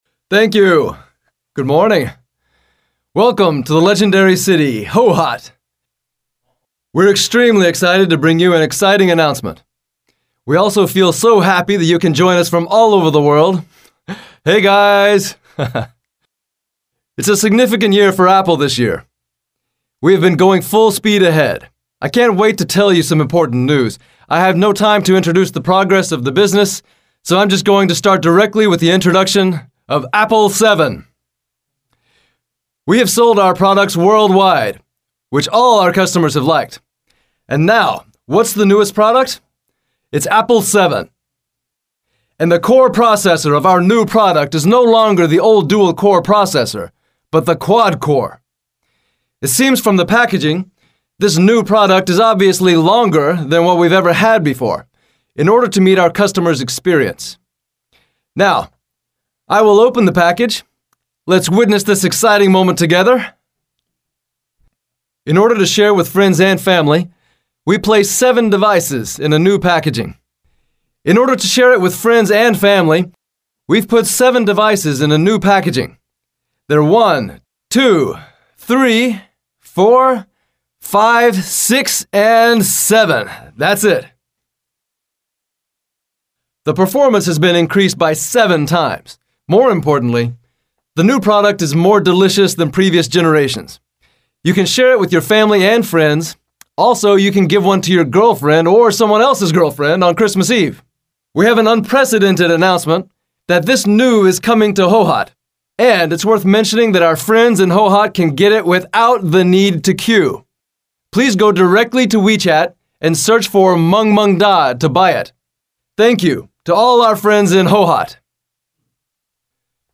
抒情 力度 讲述 稳重 激情 甜美